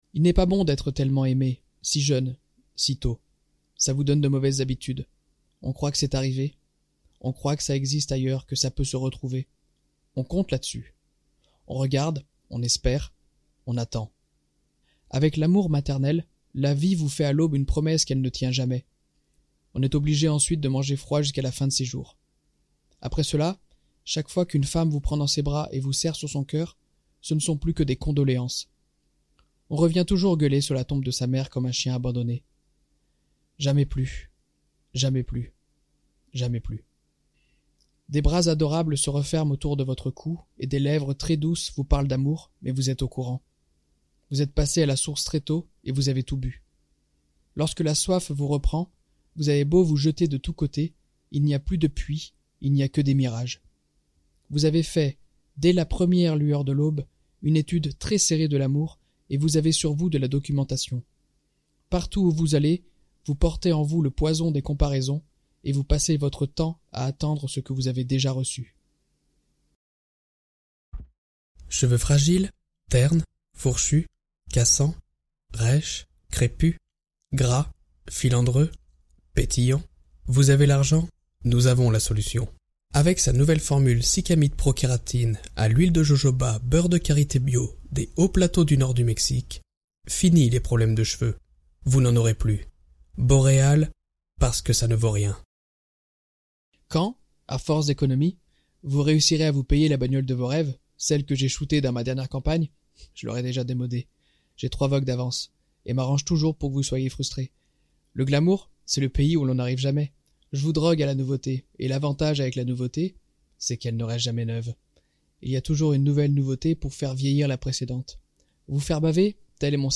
Bande démo son
25 - 40 ans - Ténor